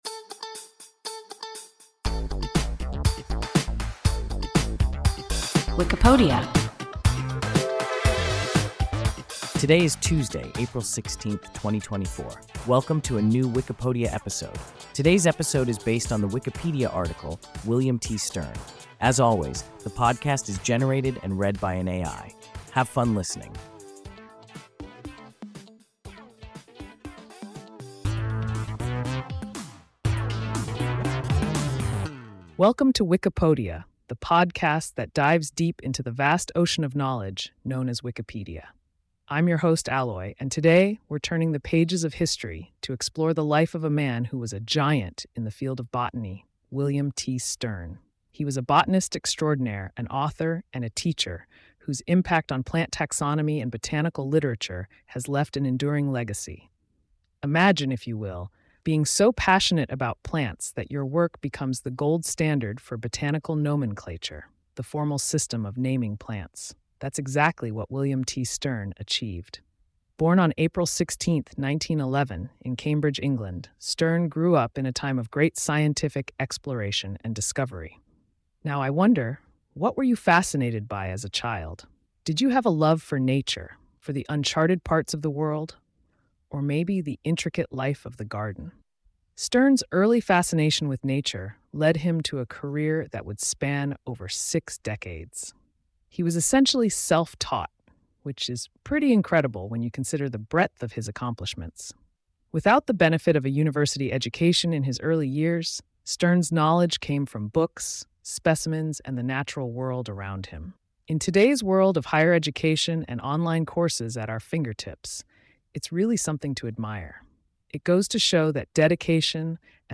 William T. Stearn – WIKIPODIA – ein KI Podcast